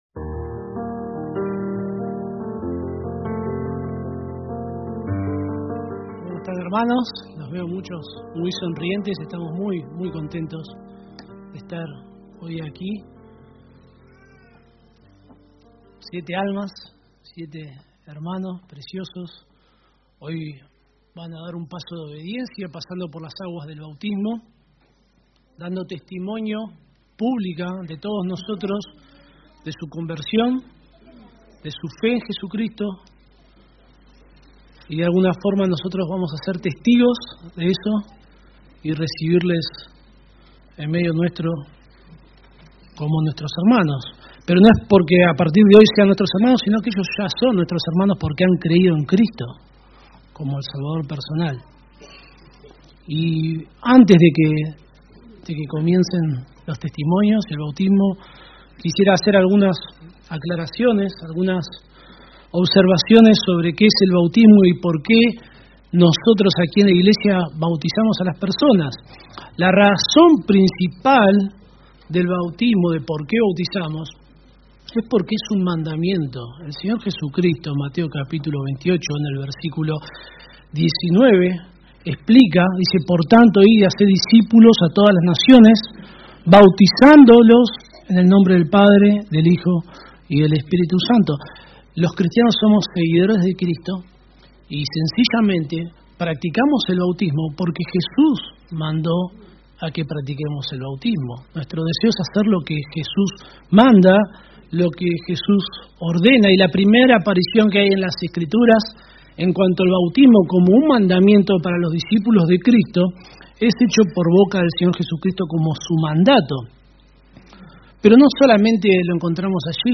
Servicio de Bautismo – Examinaos a vosotros mismos – Iglesia en Lobos
Reina-Valera 1960 (RVR1960) Video del Sermón Audio del Sermón Descargar audio Temas: